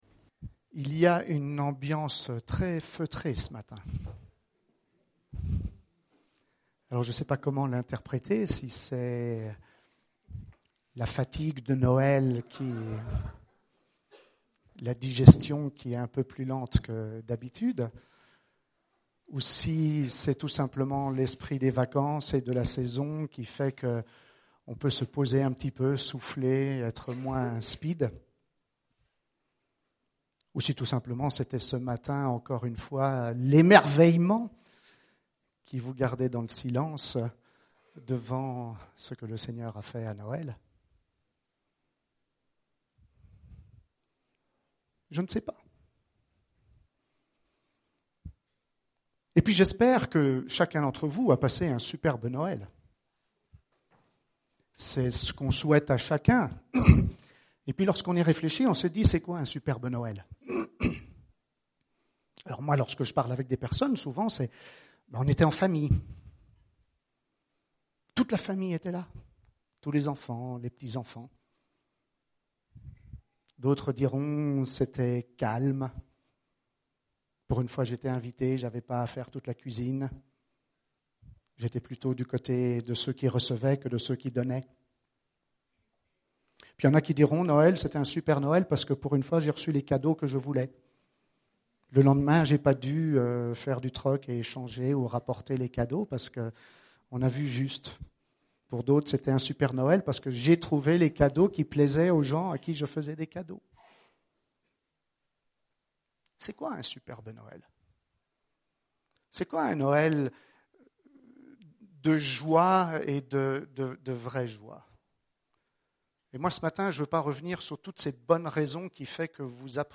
Culte du 27 décembre